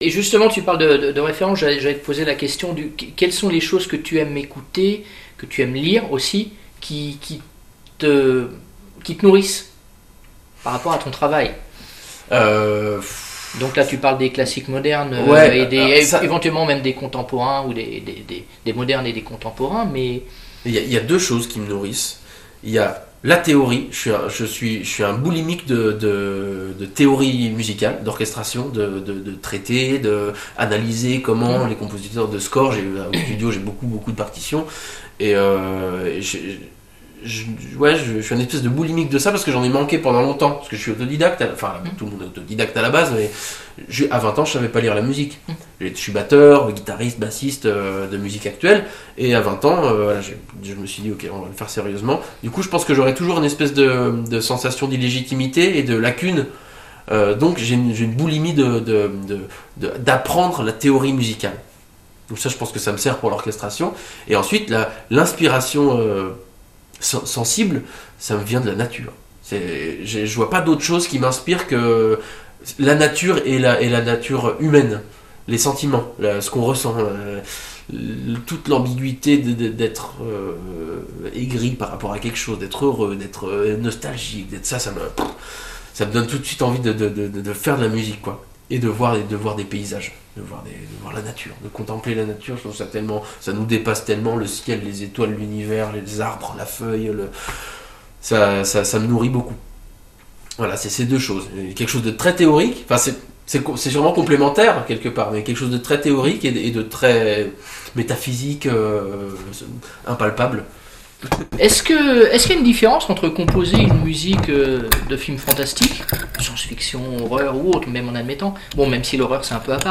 interview du compositeur